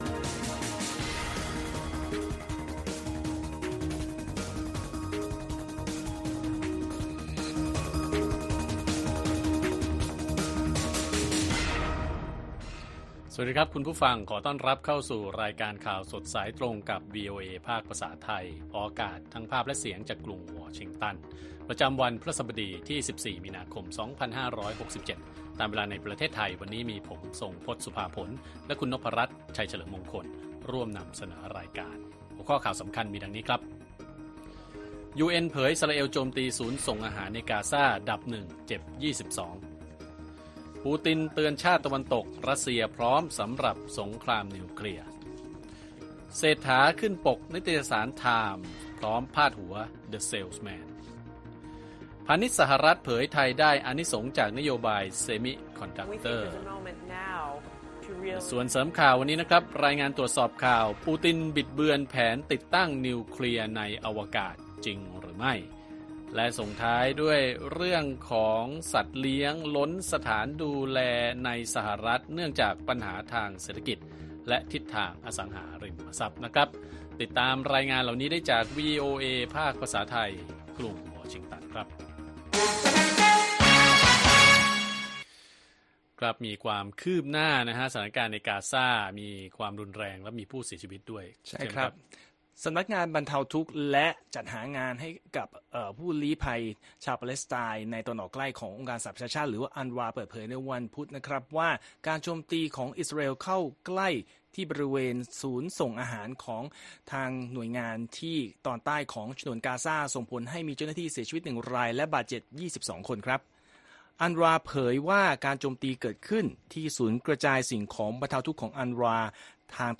ข่าวสดสายตรงจาวีโอเอไทย วันพฤหัสบดี ที่ 14 มี.ค. 2567